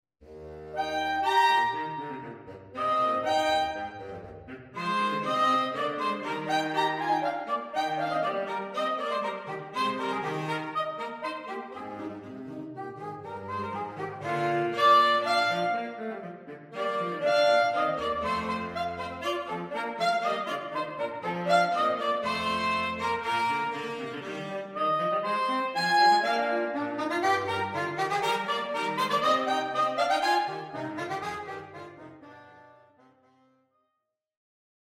A short extract from the opening Allegro
These flexible pieces can be played as either Duets or Trios